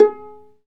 Index of /90_sSampleCDs/Roland - String Master Series/STR_Viola Solo/STR_Vla Pizz